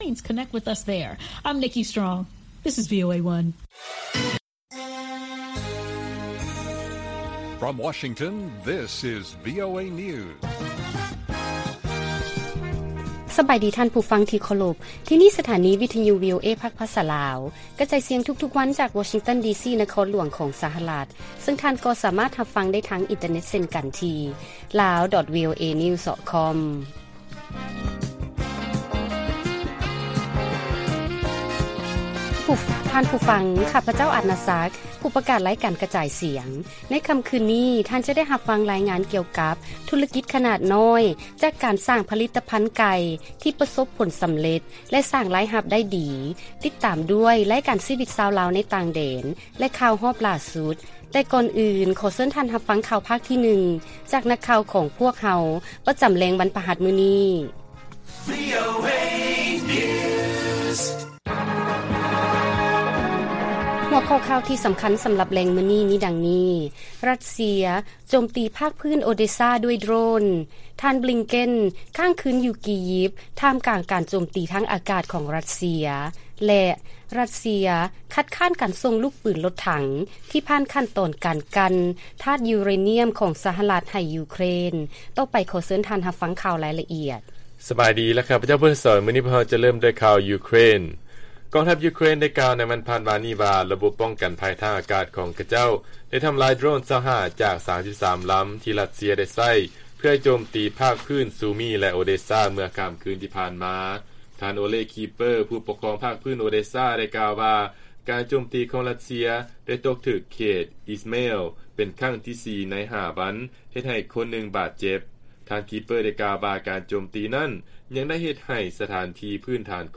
ລາຍການກະຈາຍສຽງຂອງວີໂອເອ ລາວ: ຣັດເຊຍ ໂຈມຕີພາກພື້ນ ໂອເດຊາ ດ້ວຍໂດຣນ